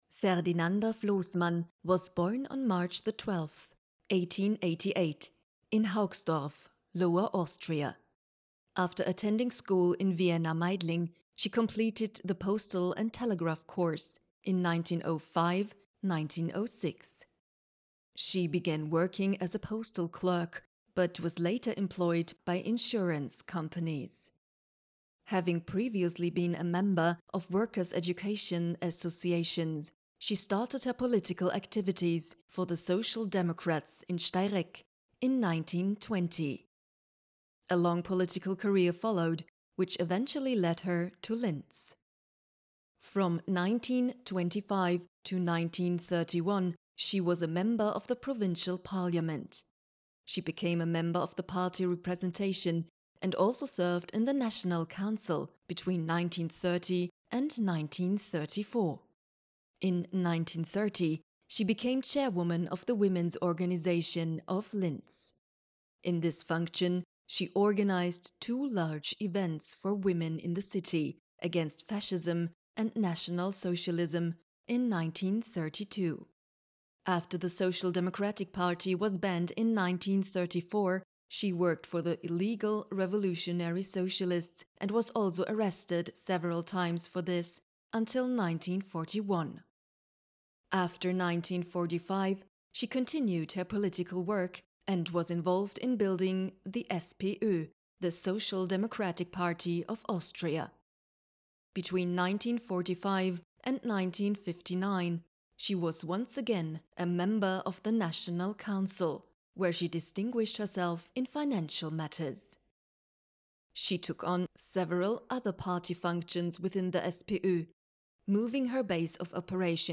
Audioguide Ferdinanda Floßmann Englisch